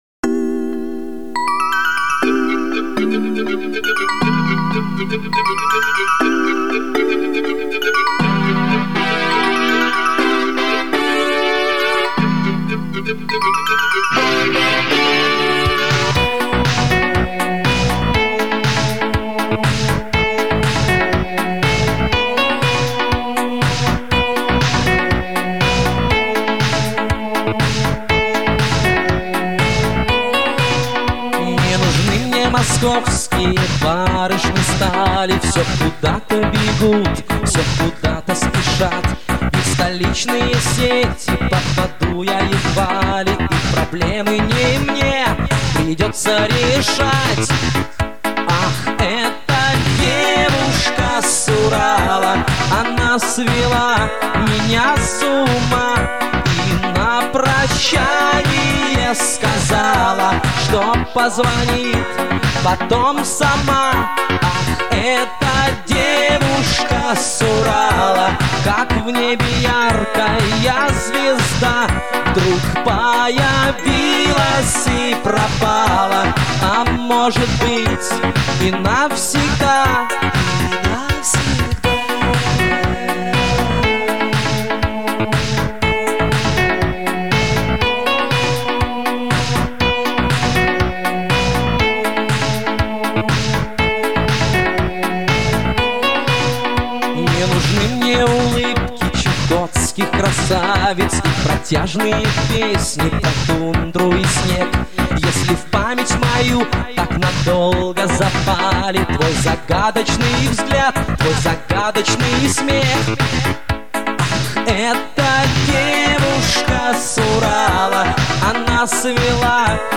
Душевная песенка.